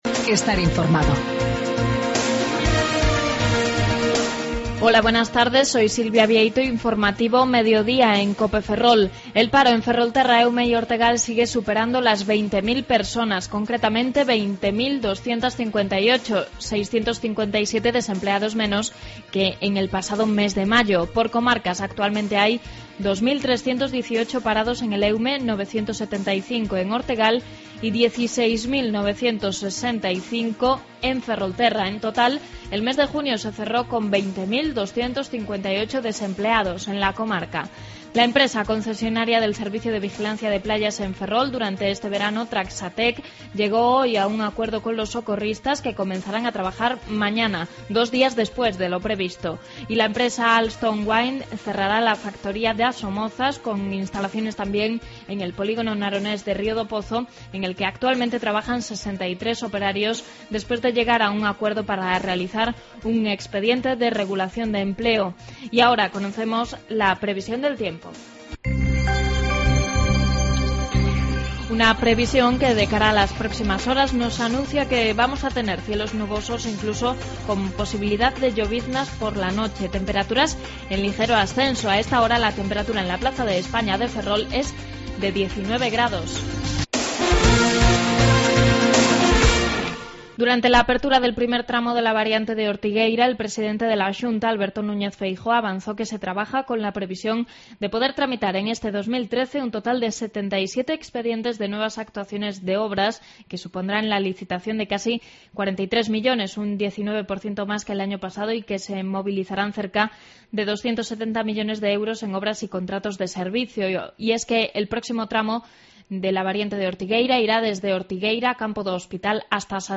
AUDIO: Informativo que aborda la actualidad de Ferrolterra, Eume y Ortegal.